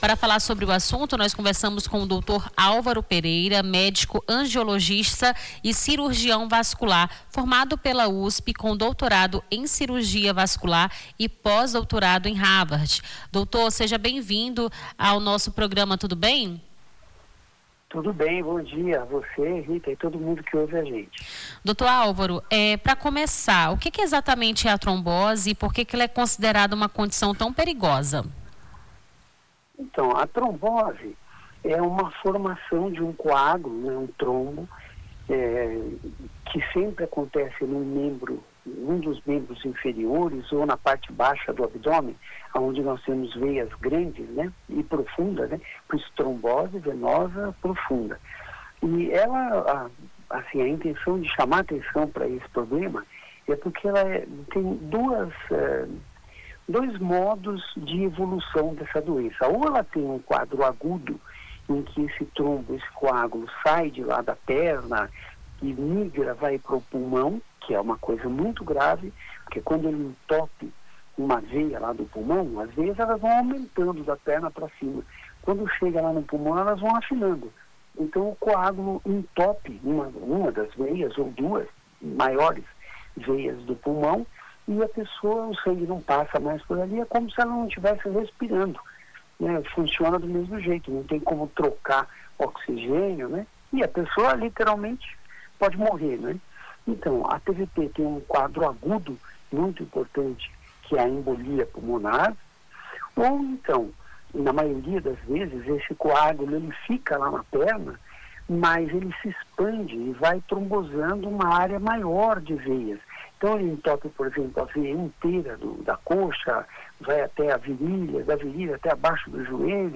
Baixar Esta Trilha Nome do Artista - CENSURA - ENTREVISTA (DIA MUNDIAL DA TROMBOSE) 13-10-25.mp3 Foto: internet/ Freepik Facebook Twitter LinkedIn Whatsapp Whatsapp Tópicos Rio Branco Acre trombose cuidados